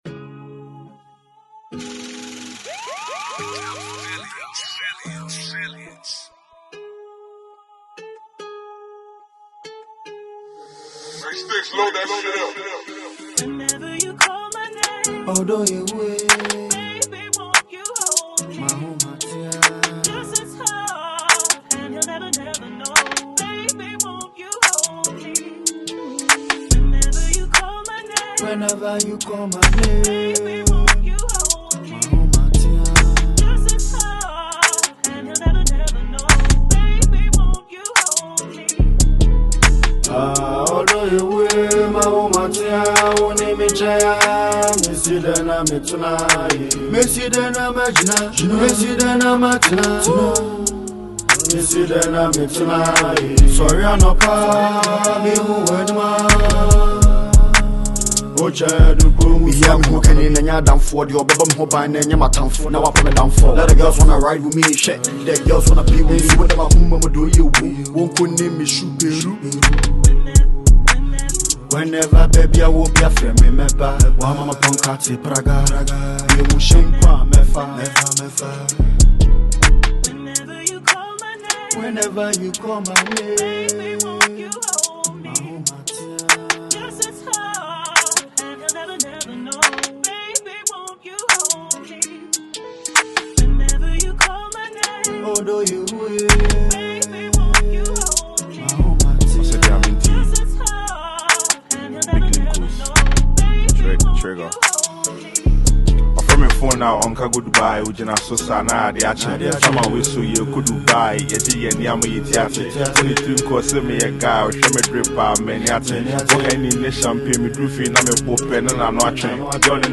a talented Asakaa rapper